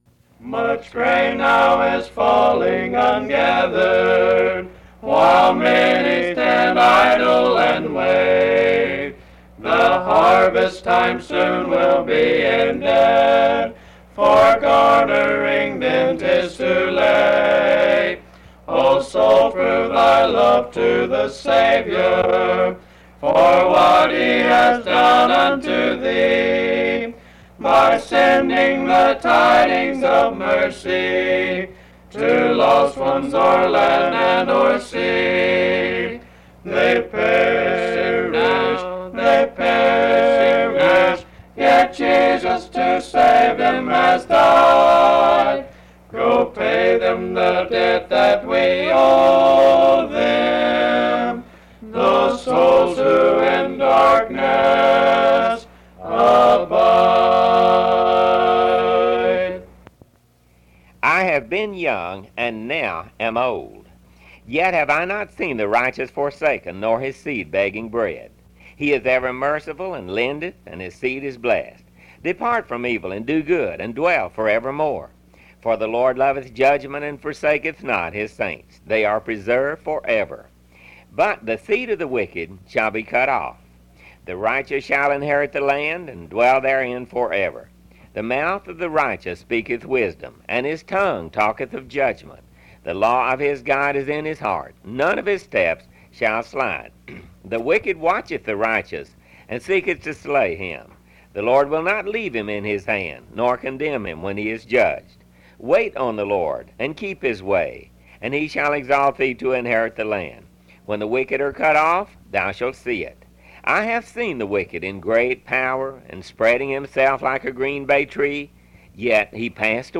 The Mission Trail Audio Broadcasts